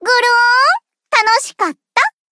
贡献 ） 分类:蔚蓝档案语音 协议:Copyright 您不可以覆盖此文件。
BA_V_Mutsuki_Battle_Victory_1.ogg